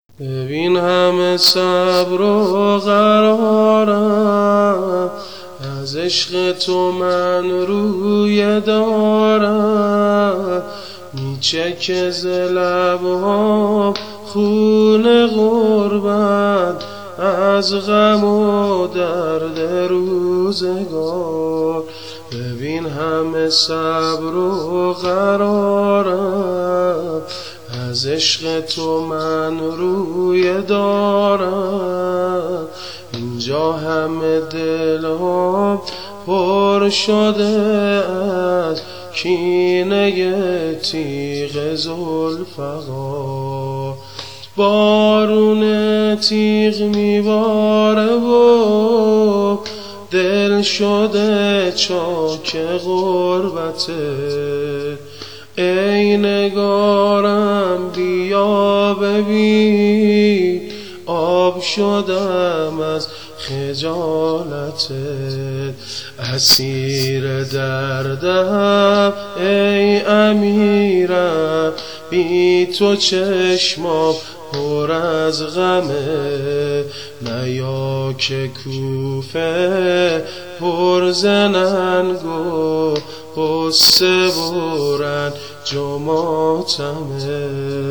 دانلود شعر و سبک واحد حضرت علی اکبر (ع) به مناسبت ماه محرم -( آئينه در هم شكسته دلاور در خون نشسته )